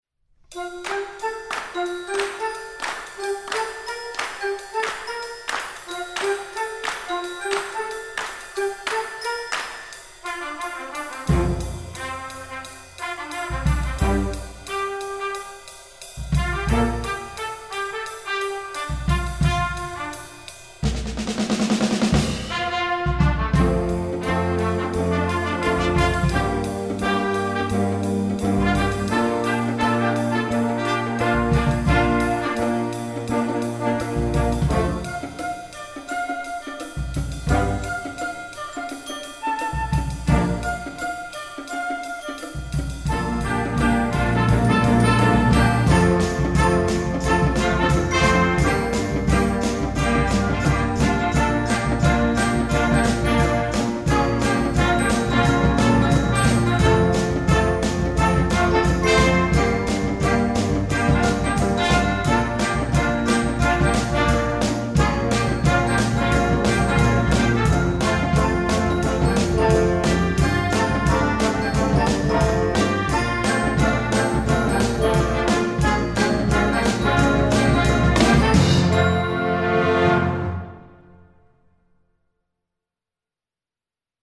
Gattung: Filmmusik für Jugendblasorchester
Besetzung: Blasorchester